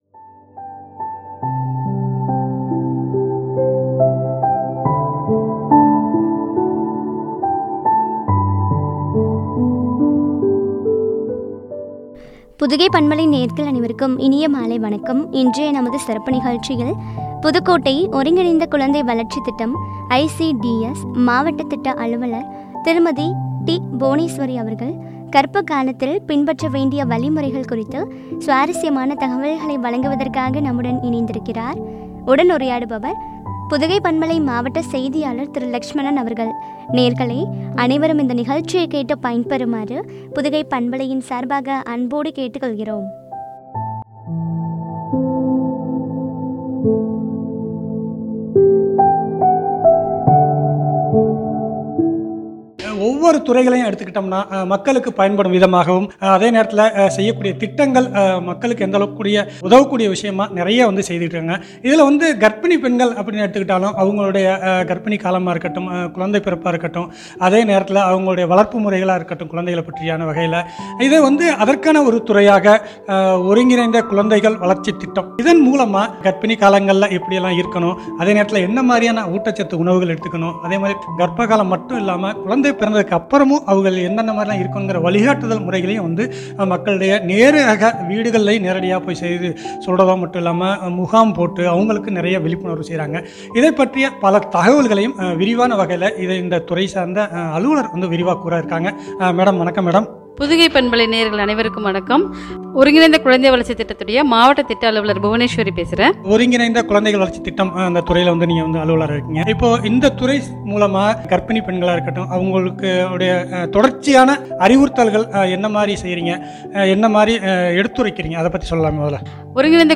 உரையாடல்